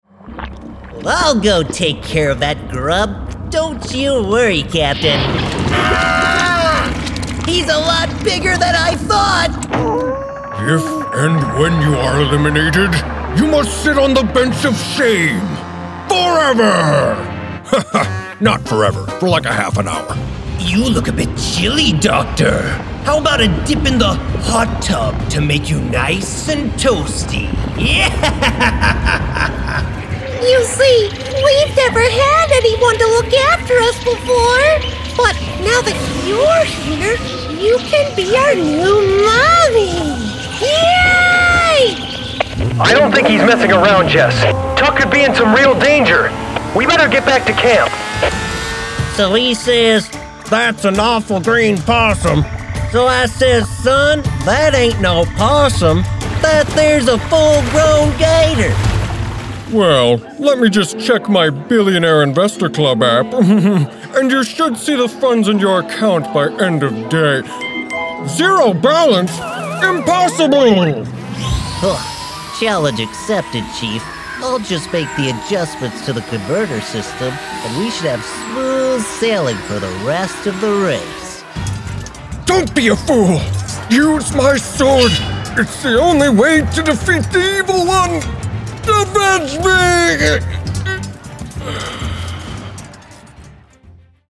Animation Demo
English - USA and Canada
Young Adult
Middle Aged